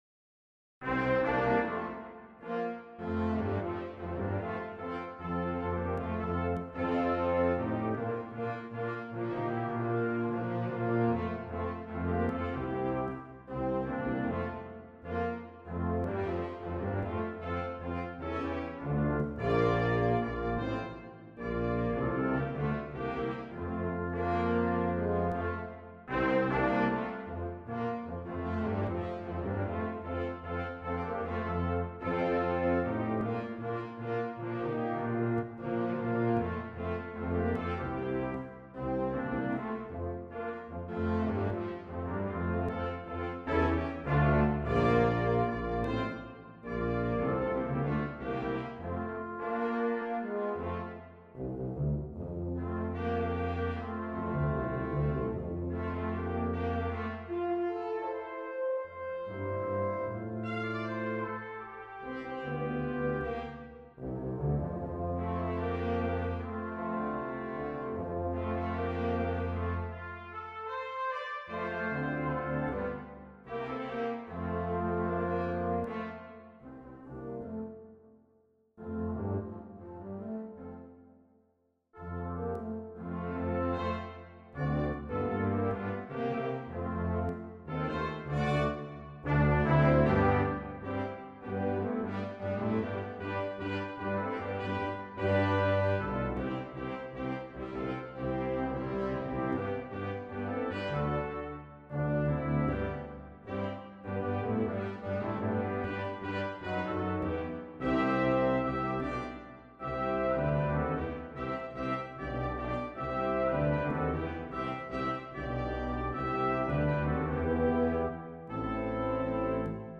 2 Trumpets,Horn,Trombone,Tuba